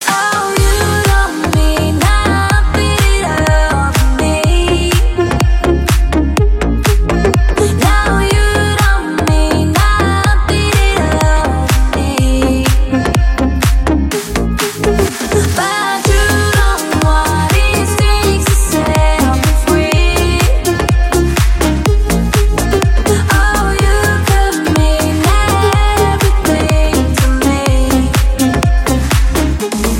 Стиль: club house